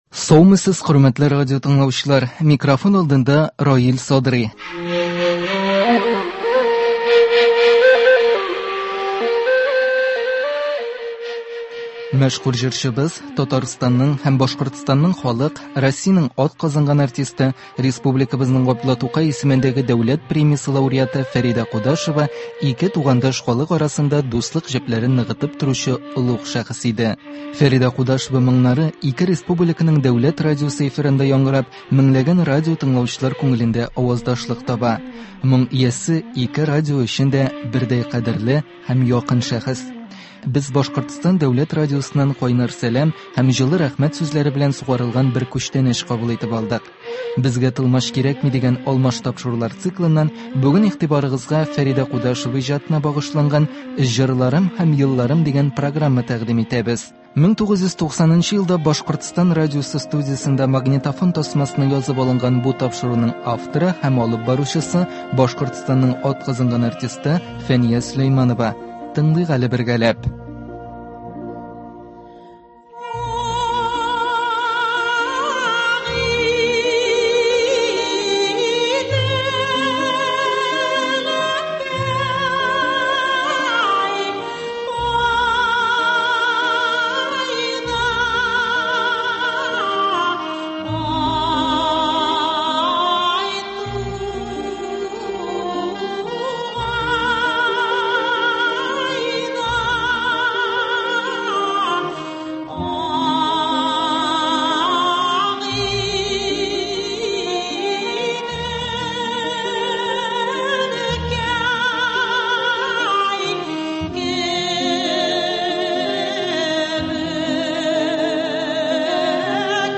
Фәридә Кудашева иҗатына багышланган әдәби-музыкаль композиция.